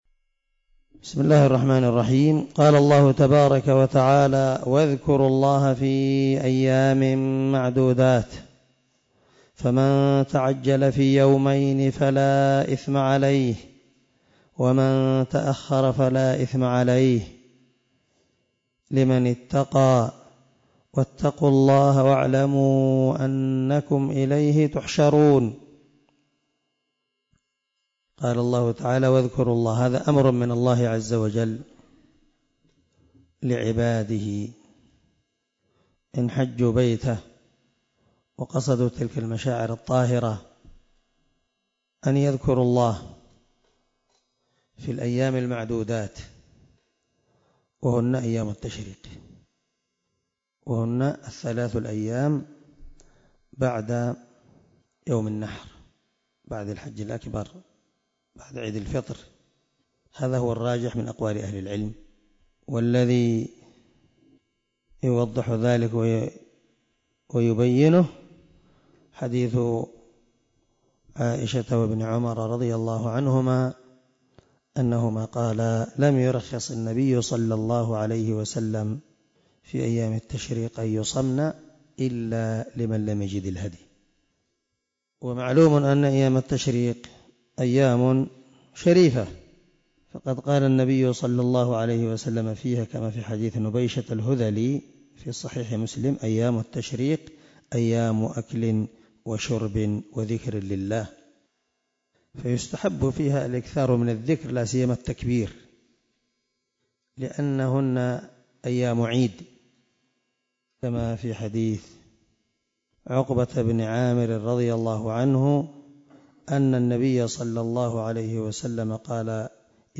096الدرس 86 تفسير آية ( 203 ) من سورة البقرة من تفسير القران الكريم مع قراءة لتفسير السعدي